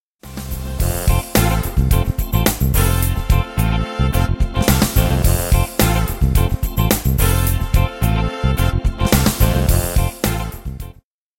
Example 2a (break fill triggered late: only the rhythm parts of the fill-in will sound)
In this case the drums will make a break, but the instrumetal parts continue to play.
BreakRhy.mp3